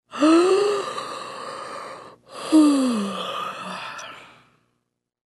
На этой странице собраны натуральные звуки зевания и потягиваний — от утренних до вечерних.
Звук продолжительного зевания женщины среднего возраста